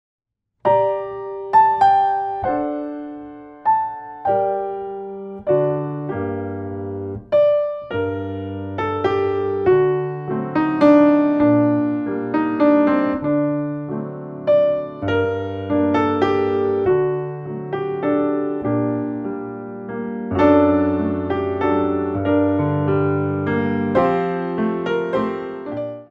4 bar intro 3/4
64 bars